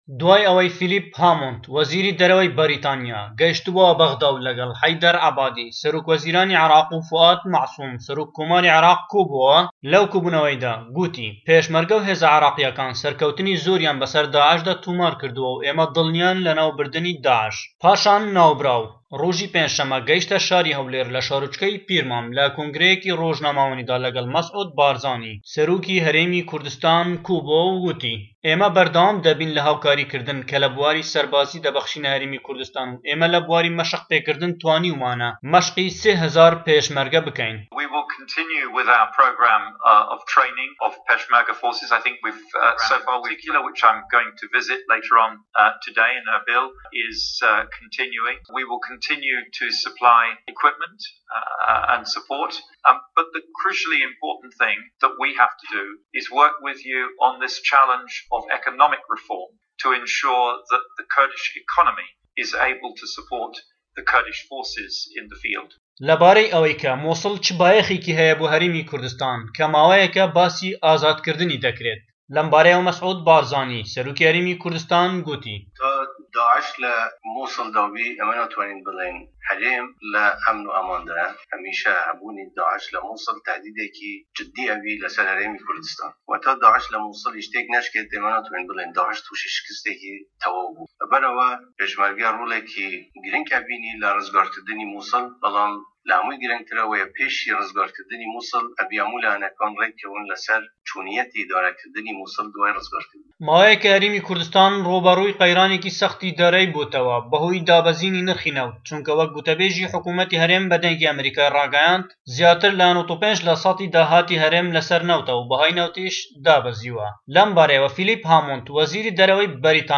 وەزیری دەرەوەی بەریتانیا ئەمڕۆ پێنجشه‌ممه‌ گەیشتە شاری هەولێر و له‌ شارۆچكه‌ی پیرمام له‌ كۆنگره‌یه‌كی رۆژنامه‌وانیدا له‌گه‌ڵ مه‌سعود بارزانی سه‌رۆكی هه‌رێمی كوردستان ووتی " ئێمه‌ به‌رده‌وام ده‌بین له‌ هاوكاریكردن له‌ بواری سه‌ربازی بۆ هه‌رێمی كوردستان و ئێمه‌ له‌ بواری مه‌شق پێكردن توانیومانه‌ مه‌شقی بە سێ هه‌زار پێشمه‌رگه‌ بكه‌ین